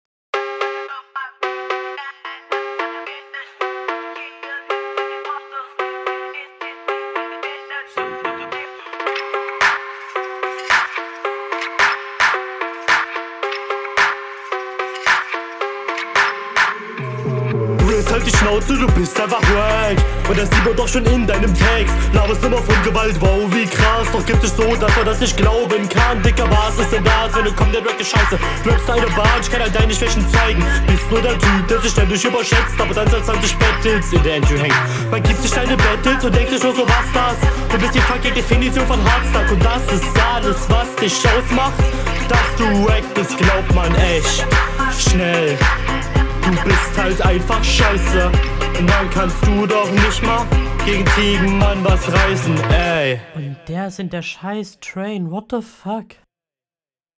Flow: Komische Betonungen, da fehlt wahrscheinlich einfach die Routine.